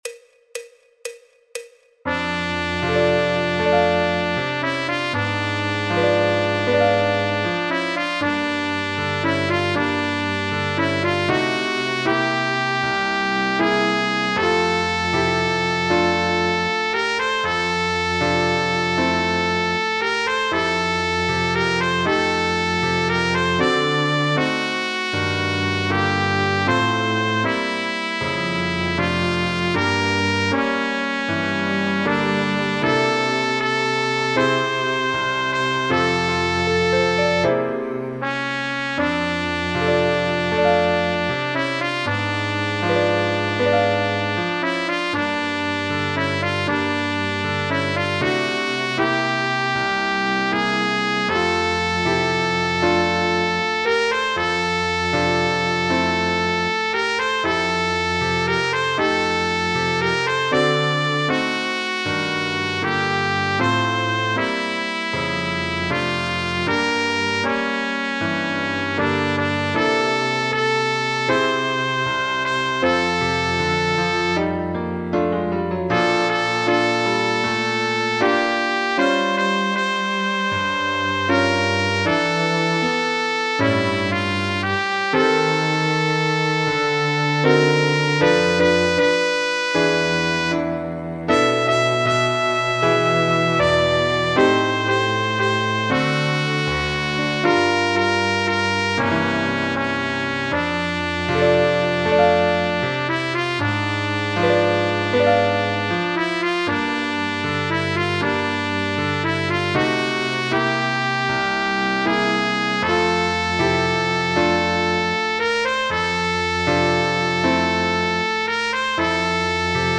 El MIDI tiene la base instrumental de acompañamiento.
Trompeta / Fliscorno
Sol Mayor
Jazz, Popular/Tradicional